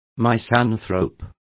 Complete with pronunciation of the translation of misanthropes.